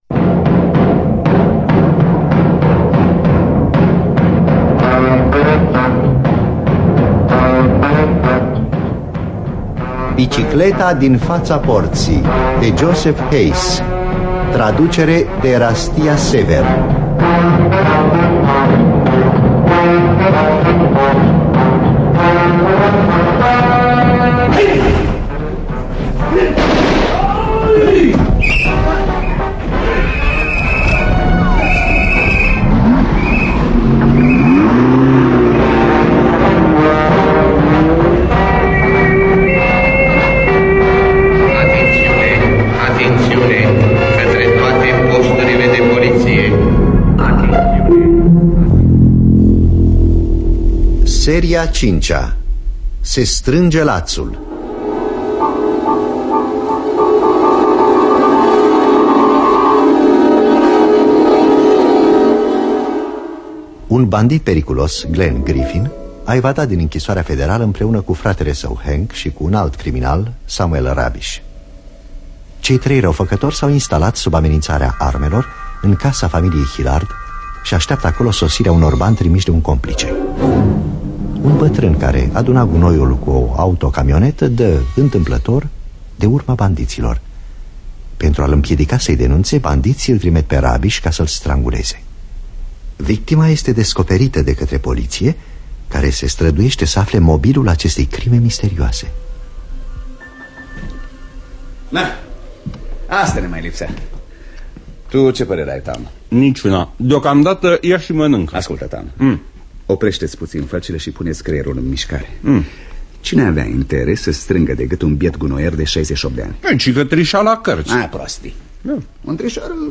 Înregistrare din anul 1968.